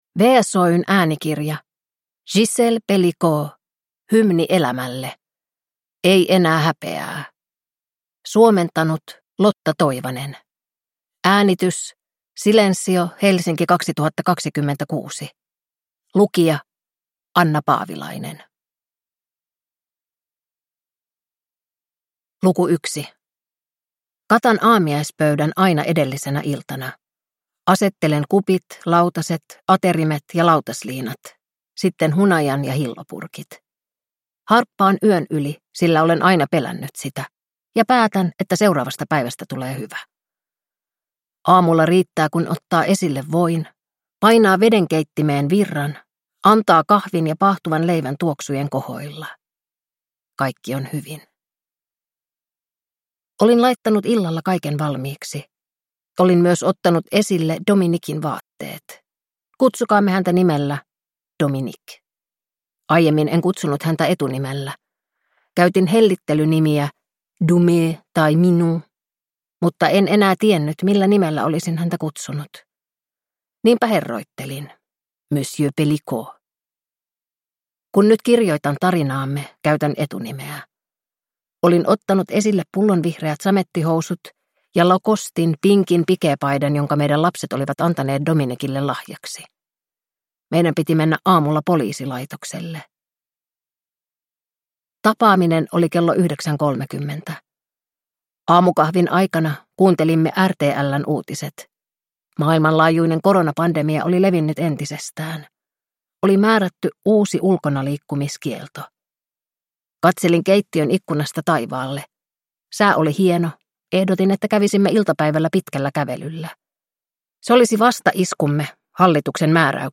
Hymni elämälle – Ei enää häpeää – Ljudbok